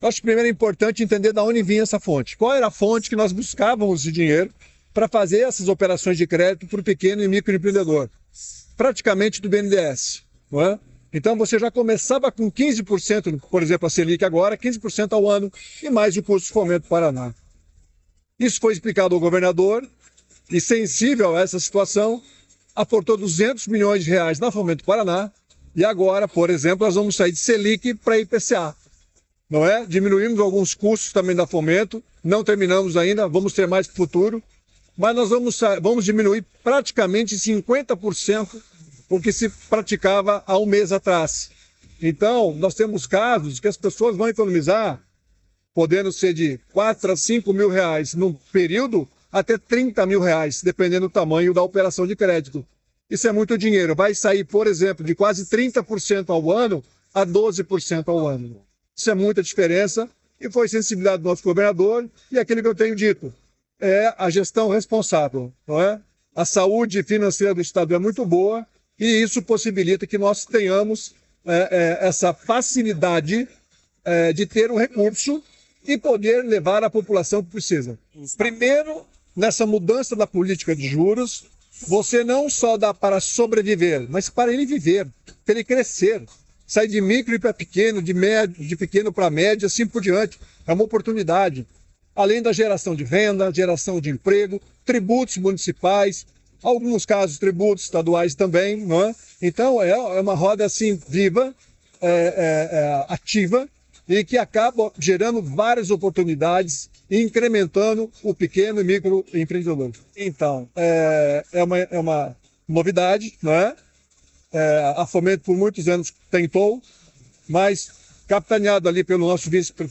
Sonora do diretor-presidente da Fomento Paraná, Claudio Stabile, sobre a redução das taxas de juros e acordo com a Prefeitura de Curitiba para incentivar microcrédito